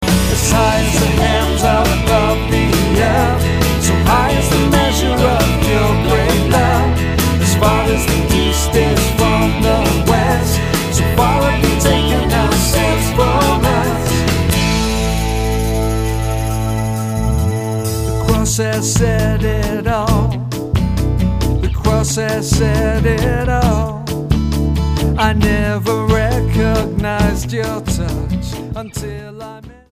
STYLE: Pop
recorded in a well-produced but fairly simplistic style